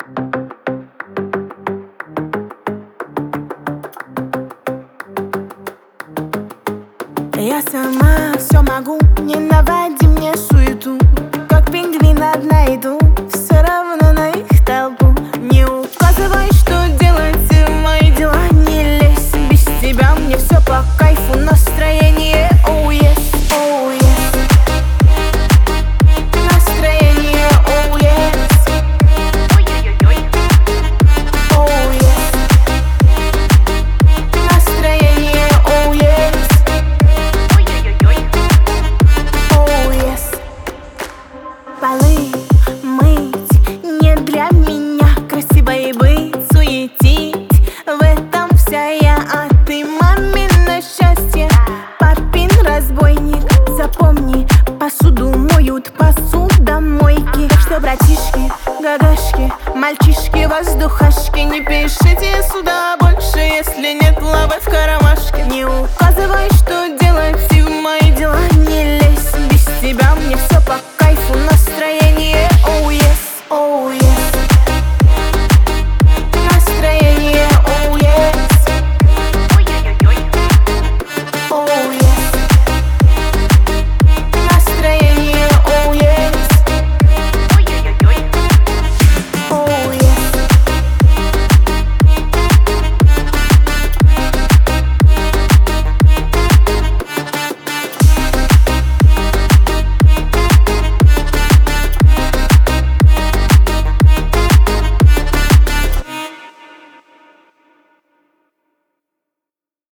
Качество: 320 kbps, stereo
Русские треки